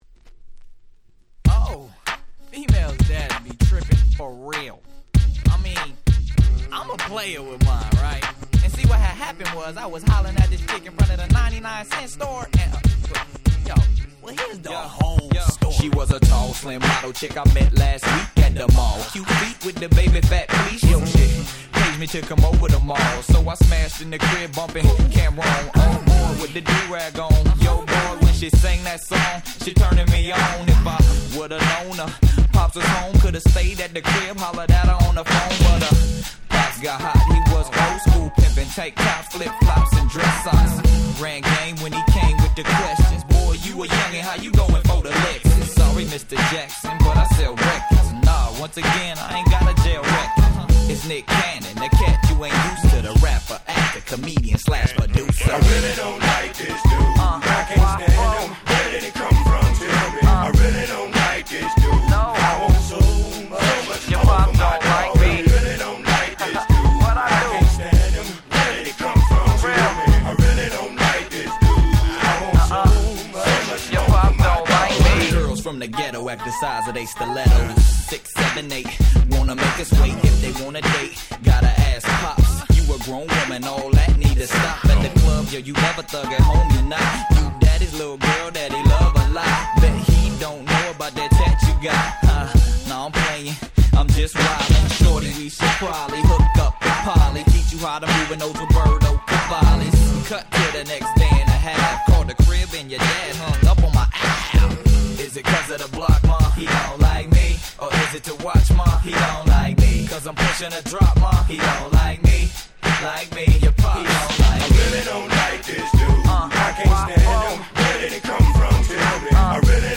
03' Very Nice Hip Hop !!
モロ使いのずっしりしたBeatが最強な超格好良いフロアチューン！！